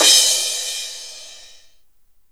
• Long Cymbal Sound Sample E Key 03.wav
Royality free cymbal tuned to the E note. Loudest frequency: 5482Hz
long-cymbal-sound-sample-e-key-03-MI1.wav